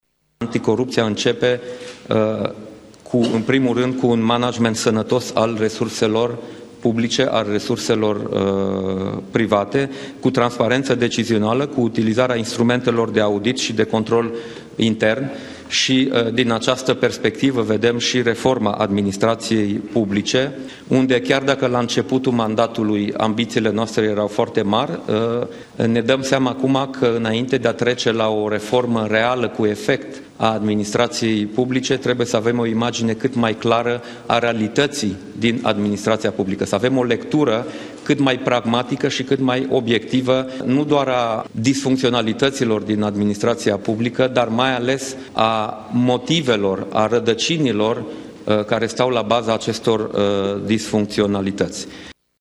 Premierul Cioloş a deschis dezbaterea publică organizată de Ministerul Justiţiei şi Cancelaria Prim-Ministrului, la Palatul Victoria.
Premierul Dacian Cioloș a arătat, în discursul de deschidere, că o țară fără corupție este o țară sănătoasă, însa eliminarea corpuției nu vine doar prin adoptarea de legi și munca justiției.